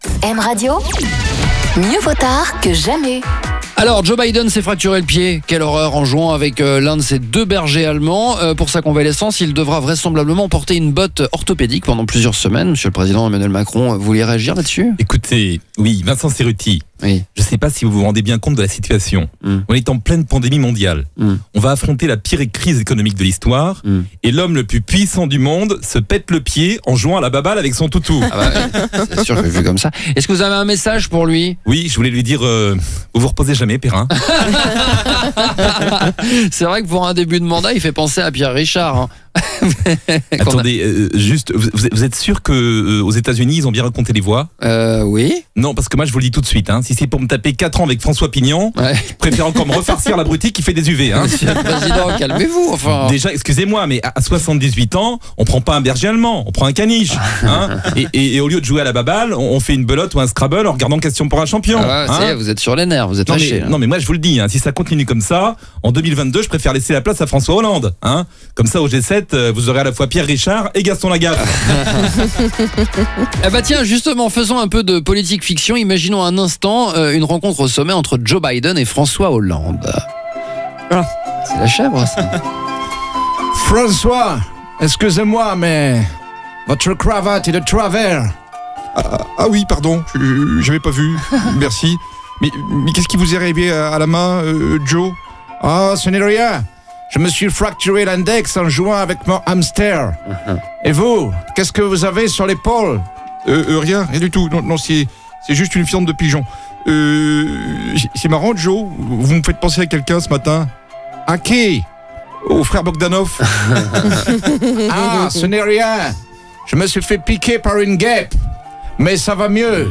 s’invite dans le M Radio Réveil pour refaire l’actualité avec ses imitations et tout le monde va y passer !